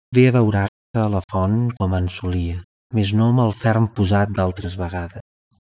speech-male_hpsModel_sines
catalan harmonic hps hpsModel male sinusoidal sms sms-tools sound effect free sound royalty free Memes